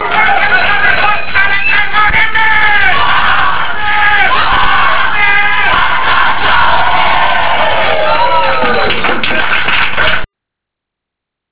Unser Schlachtruf